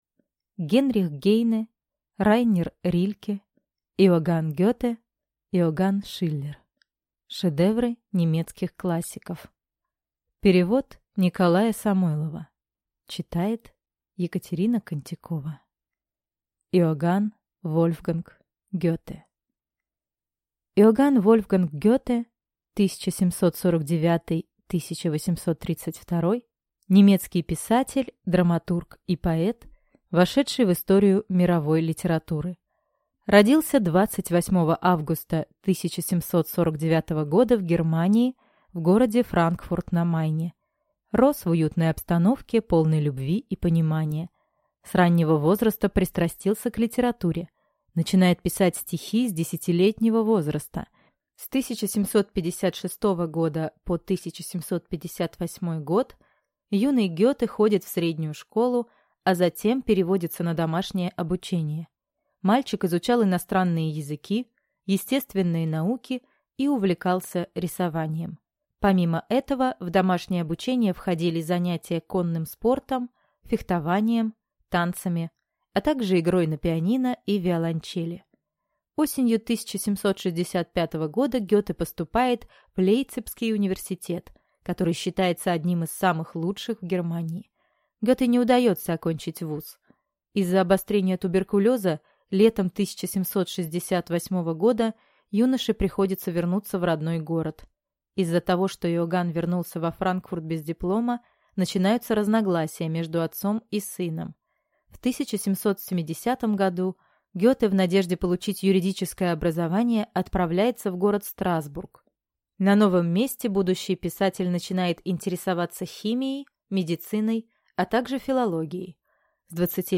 Аудиокнига Шедевры немецких классиков | Библиотека аудиокниг